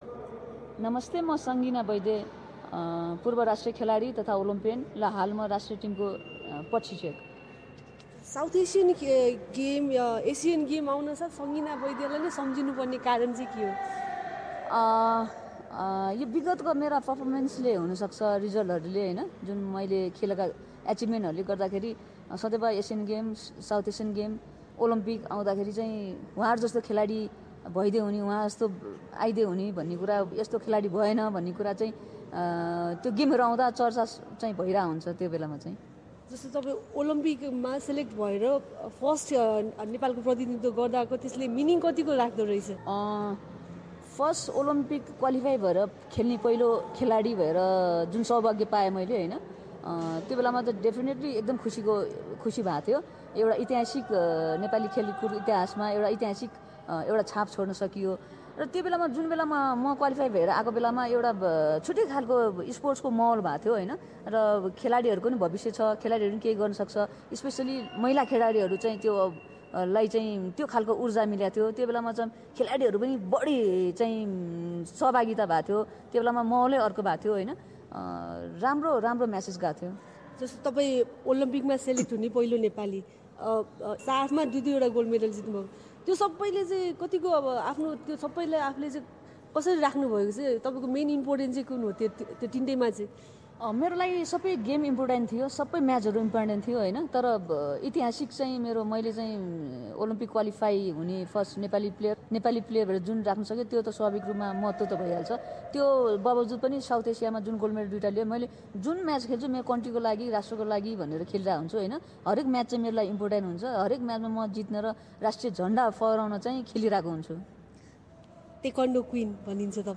यसै सन्दर्भमा उनीसँग एसबिएस नेपालीसँग गरेको कुराकानी।